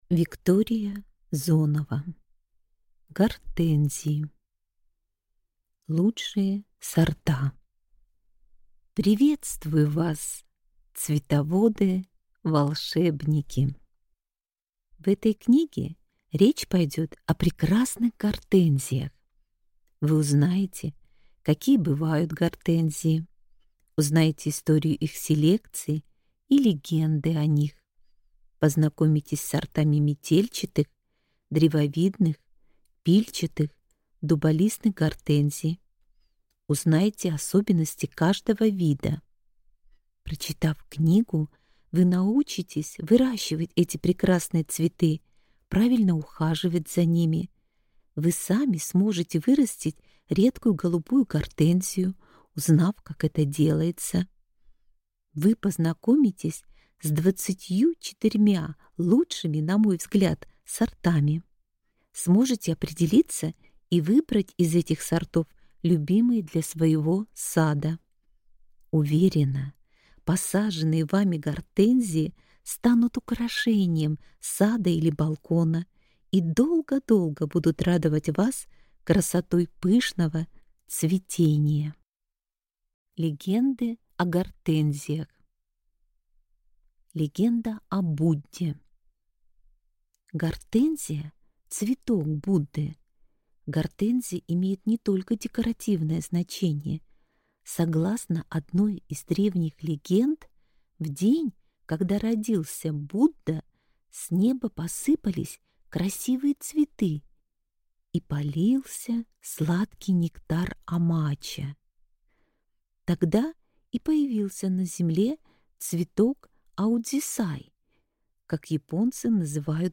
Аудиокнига Гортензии. Лучшие сорта | Библиотека аудиокниг